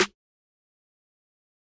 Snare (coordinate).wav